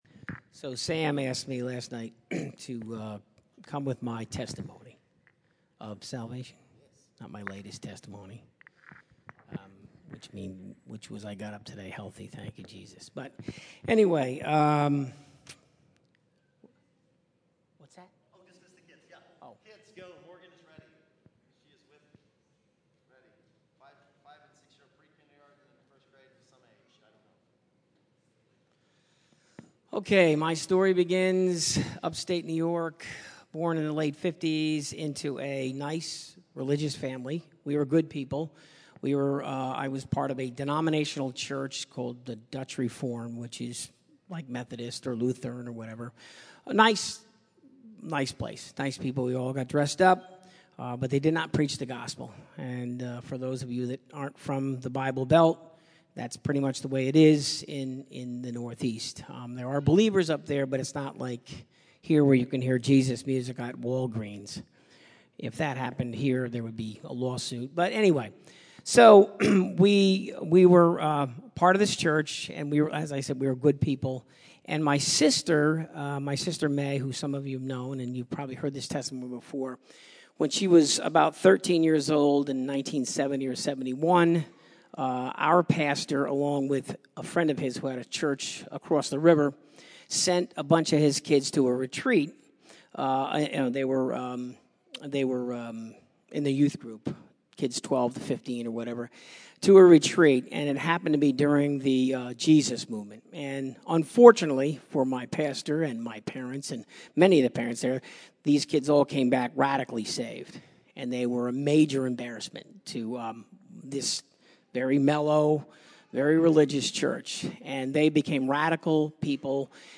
Category: Testimonies